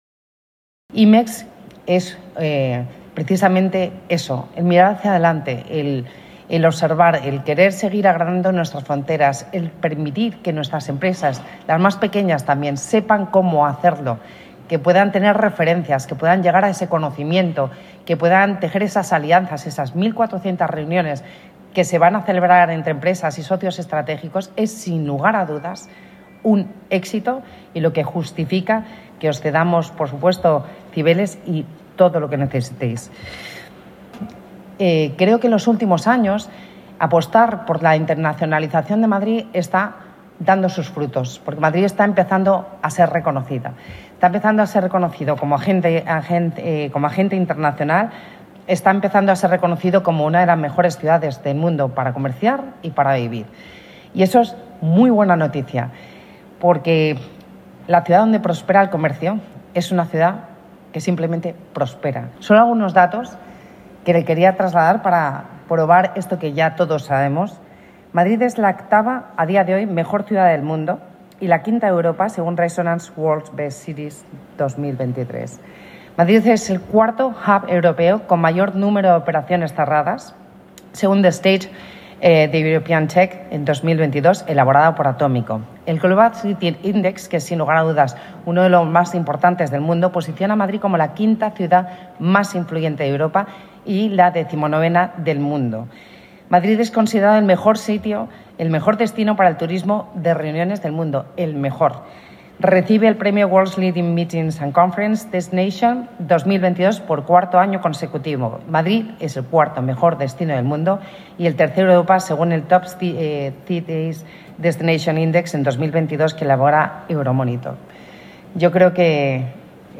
BVillacis-FeriaIMEXMadrid-08-02.mp3